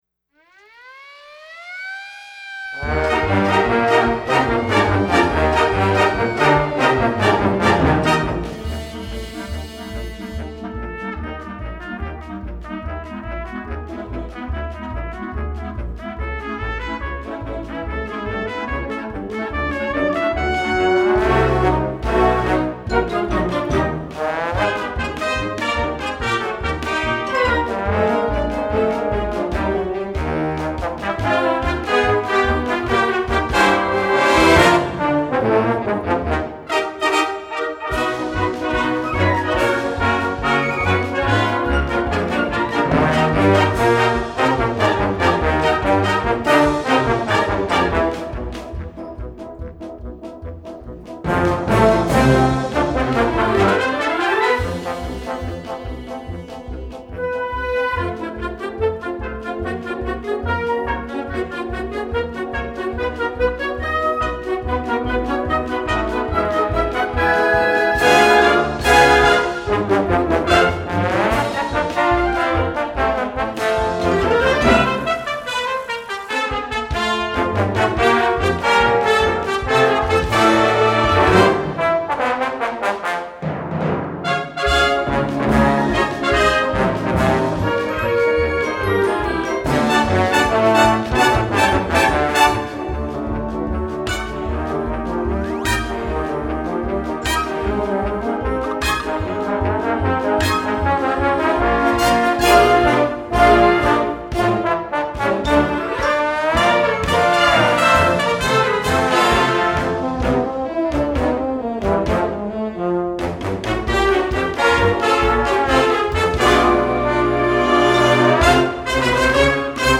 Gattung: Musical
Besetzung: Blasorchester